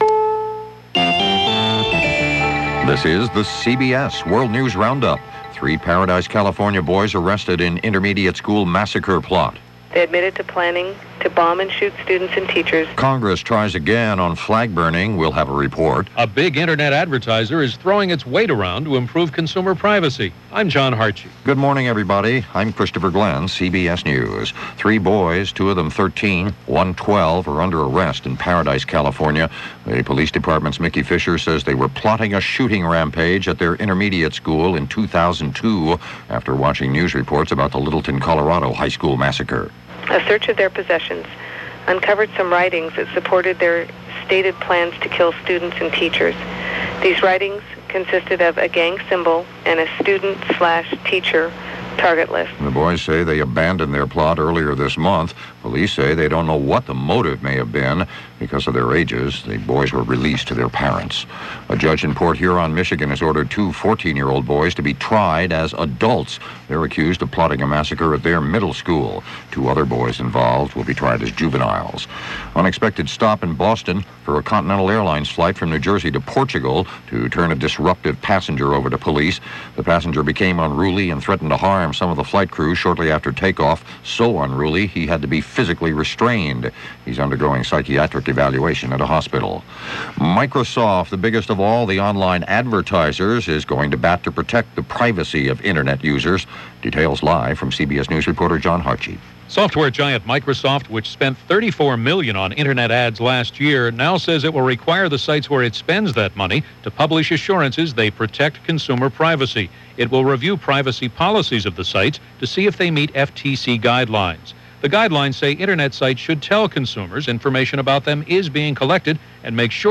And that’s just a little of what happened, this June 234, 1999 as presented by The CBS World News Roundup.